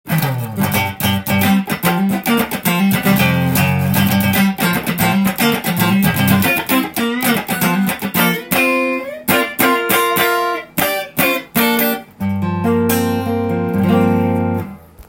フロントピックアップで弾いてみると
思わずカッティングしたくなる丁度良い丸みの音です。
ジャズやブルースなど　おしゃれな音楽にもピッタリですね！